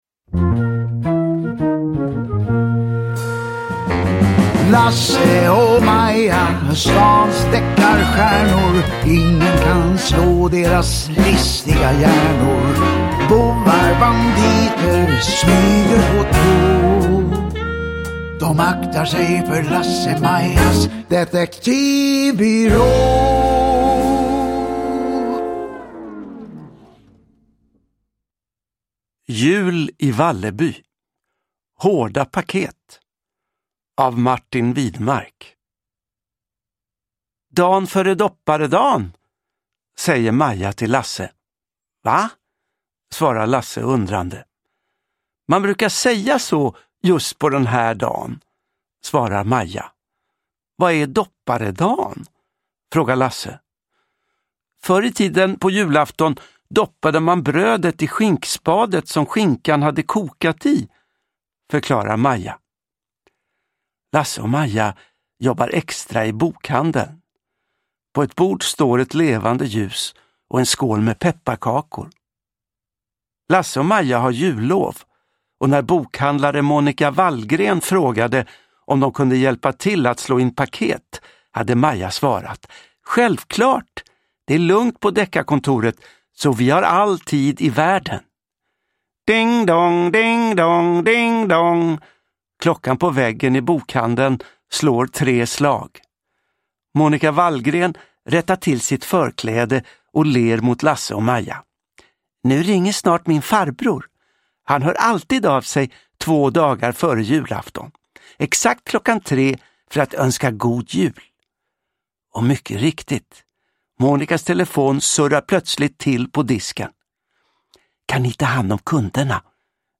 Downloadable Audiobook
Ljudbok